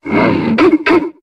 Cri de Mastouffe dans Pokémon HOME.